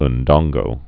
(ən-dônggō)